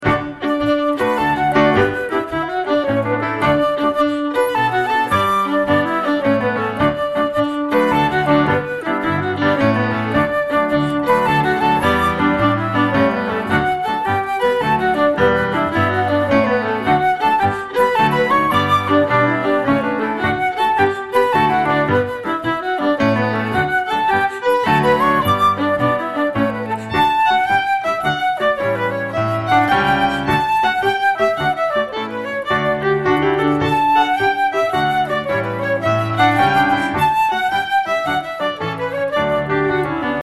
The tune for the dance is in slip jig (9/8) time.